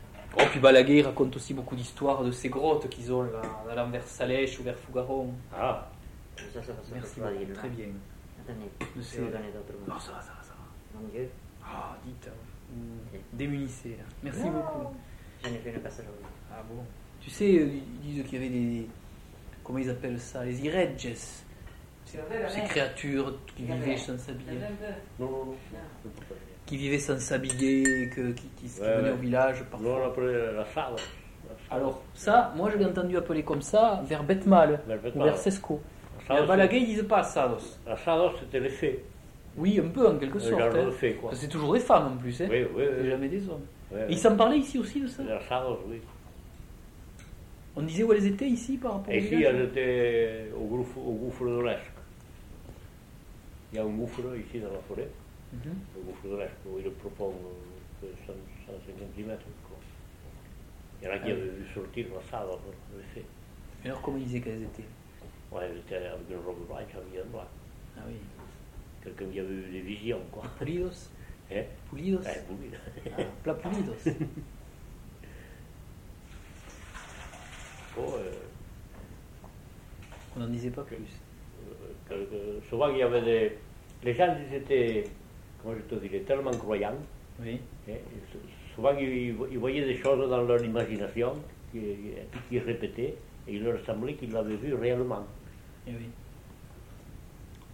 Aire culturelle : Couserans
Lieu : Pouech de Luzenac (lieu-dit)
Genre : témoignage thématique